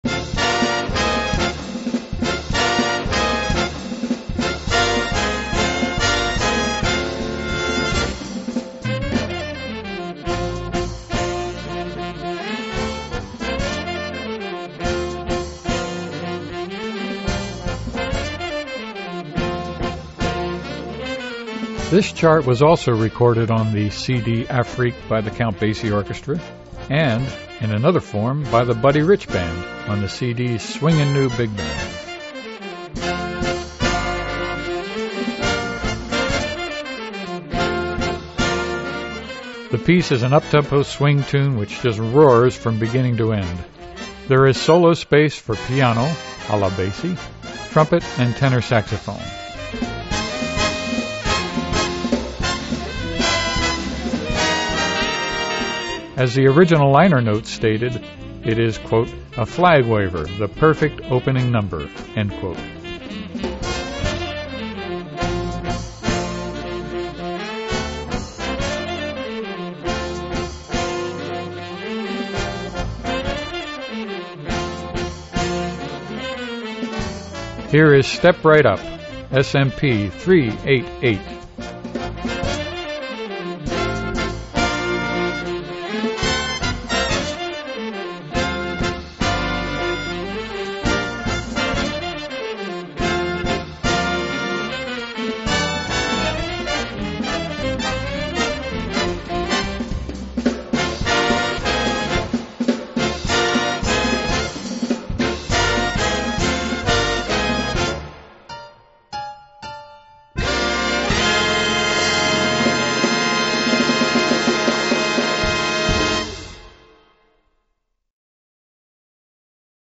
trumpet and tenor saxophone.